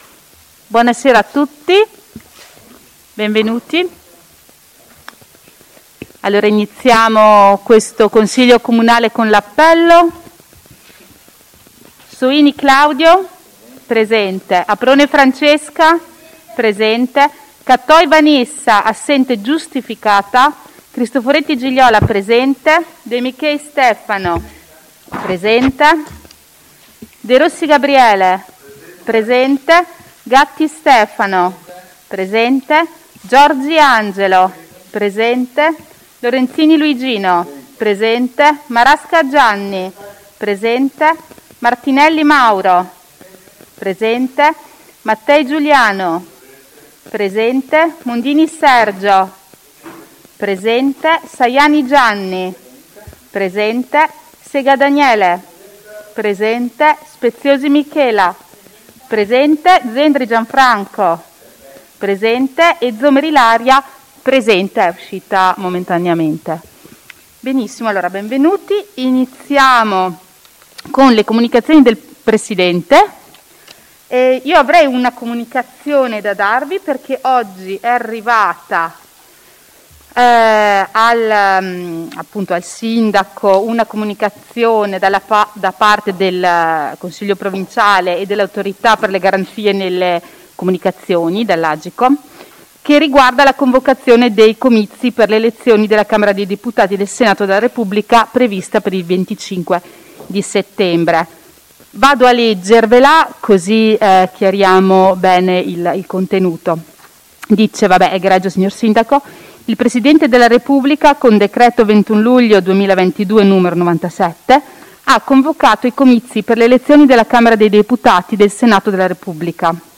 Convocazione Consiglio Comunale 28.07.2022
AudioSedutaConsiglio.mp3